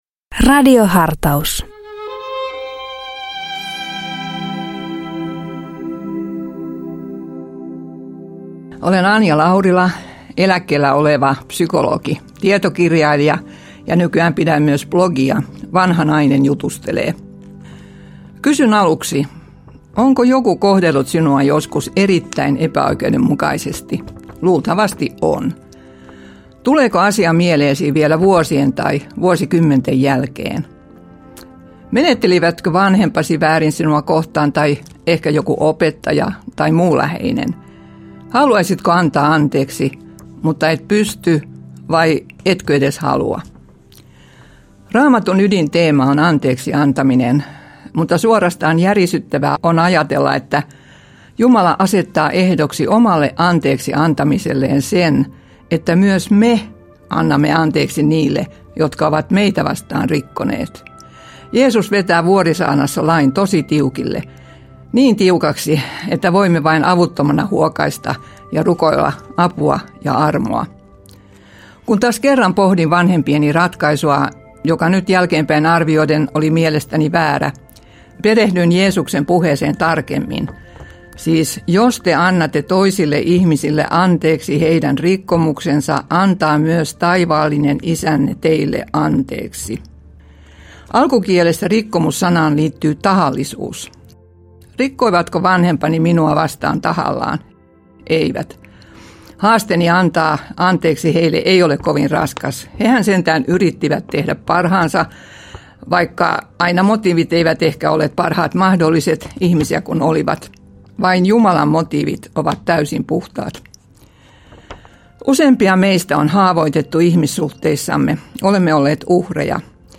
Radio Dei lähettää FM-taajuuksillaan radiohartauden joka arkiaamu kello 7.50. Radiohartaus kuullaan uusintana iltapäivällä kello 16.50. Radio Dein radiohartauksien pitäjinä kuullaan laajaa kirjoa kirkon työntekijöitä sekä maallikoita, jotka tuntevat radioilmaisun omakseen.